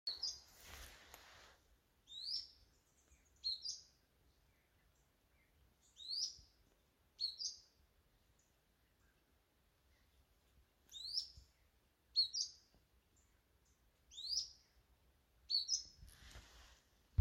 Grey-bellied Spinetail (Synallaxis cinerascens)
Location or protected area: Reserva Privada San Sebastián de la Selva
Condition: Wild
Certainty: Recorded vocal